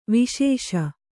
♪ viśeṣa